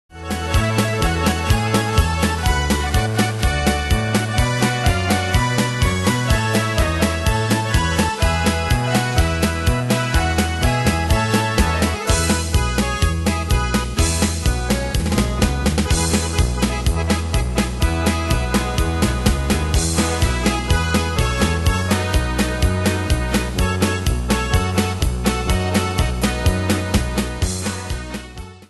Style: PopAnglo Ane/Year: 1987 Tempo: 125 Durée/Time: 3.36
Danse/Dance: Polka Cat Id.
Pro Backing Tracks